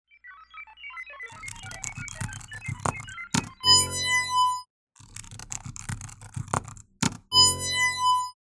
Научно-фантастический компьютер разблокировать два трека с фоновым звуком
nauchno-fantasticheskij_kompyuter_razblokirovat_dva_treka_s_fonovim_zvukom_95i.mp3